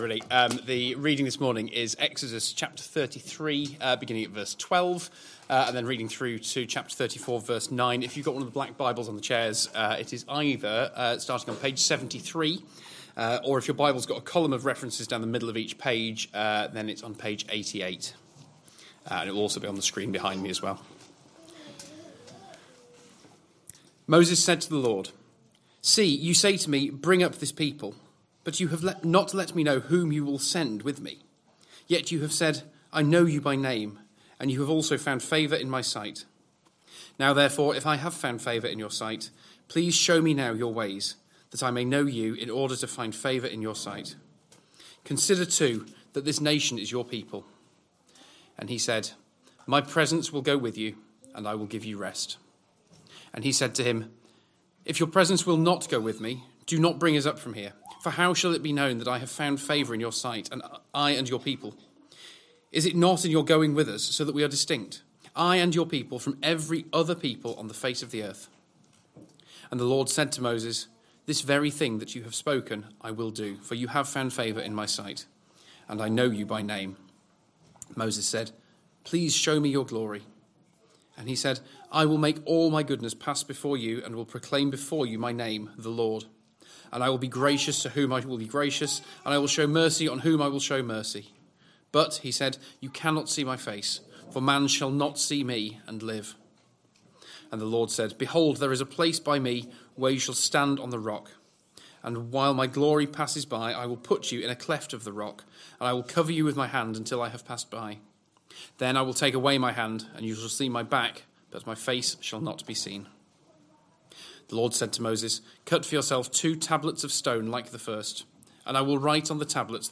Christ Church Sermon Archive
Sunday AM Service Sunday 8th February 2026 Speaker